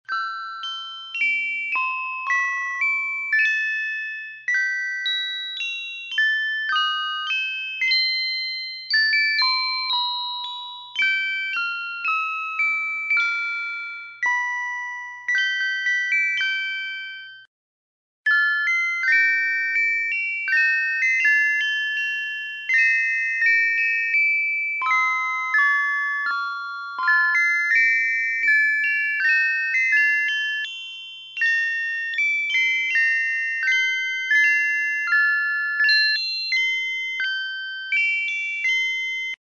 音痴版 (3.0秒)